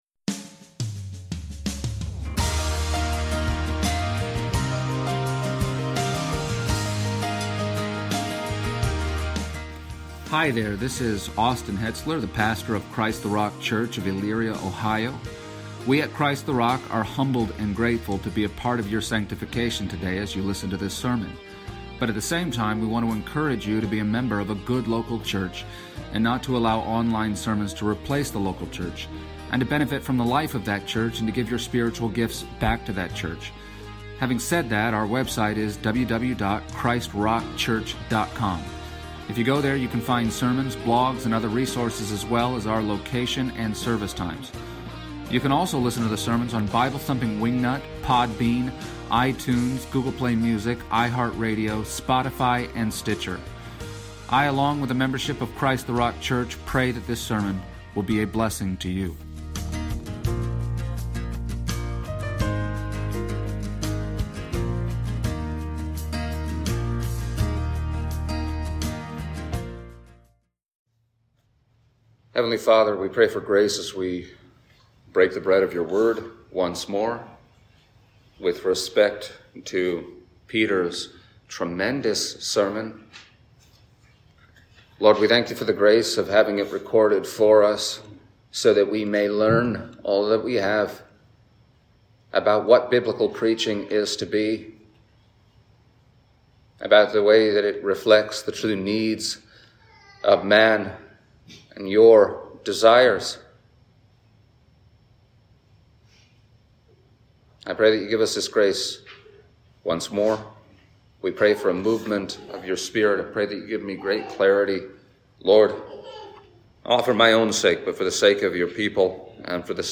Passage: Acts 2:14-42 Service Type: Sunday Morning